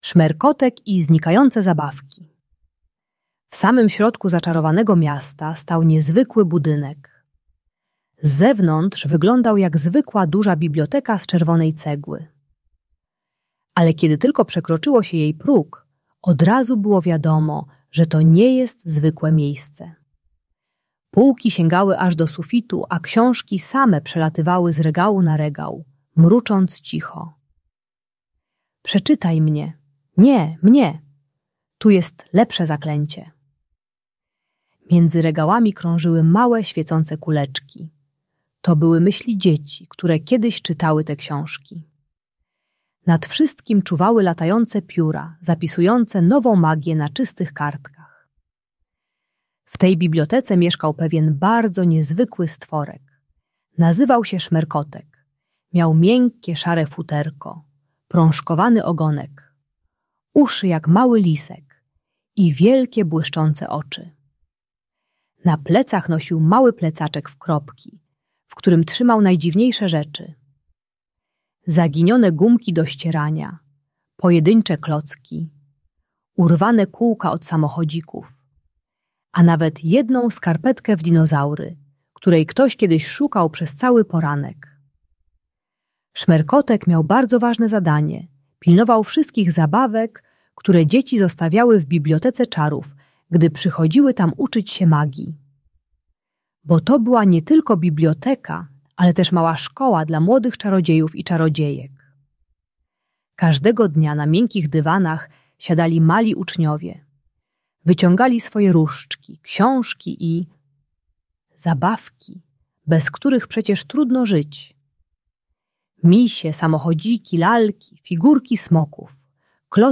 Szmerkotek i Znikające Zabawki - Bunt i wybuchy złości | Audiobajka